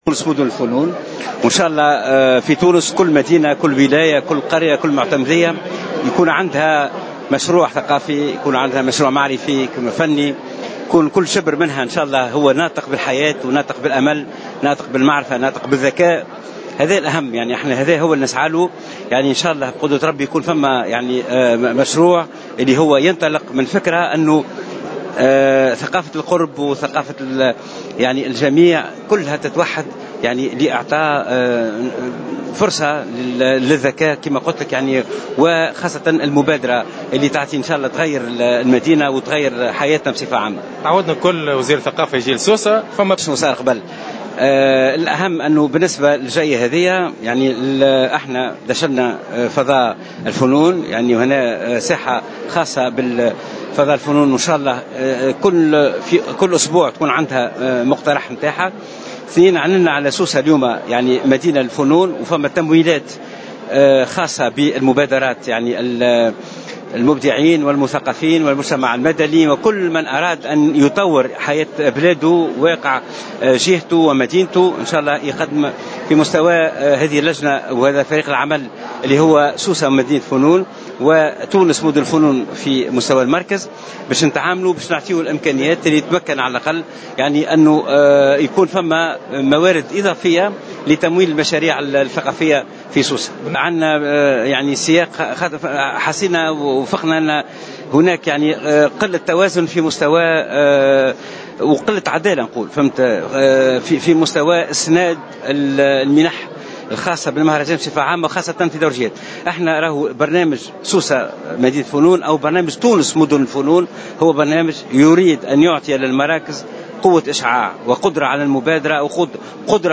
وأكد وزير الثقافة في تصريحات صحفية حرص وزارته على توفير التشجع للمبدعين والمثقفين وتقديم الدعم لمشاريعهم.